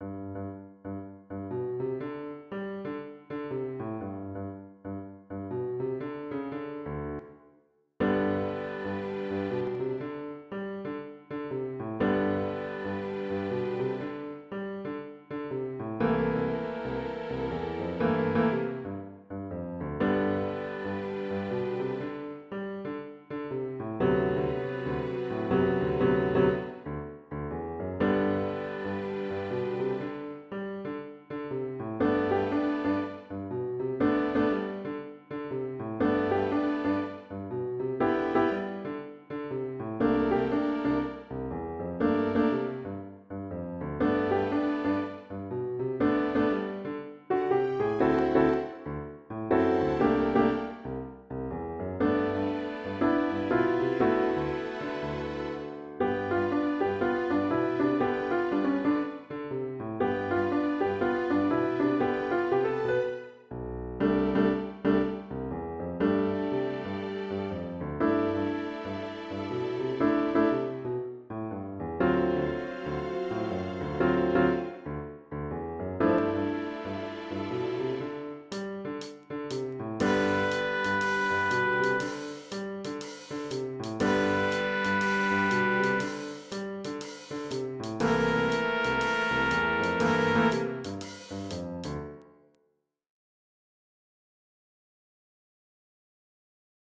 Für Ensemble
Ensemblemusik